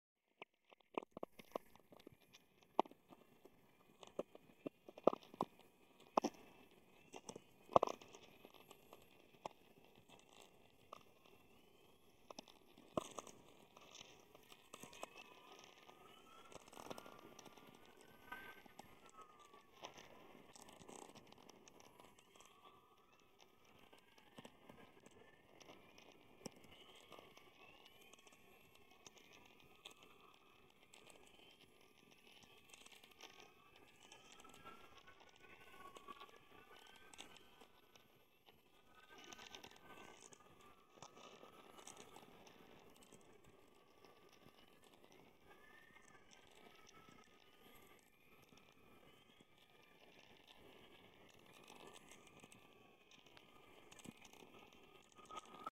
In about 5% of sightings of the northern lights it is accompanied by a hissing and cracking sound which is caused by the release of static charge, linked to changes in atmospheric electricity caused by the aurora’s disturbance to Earth’s magnetic field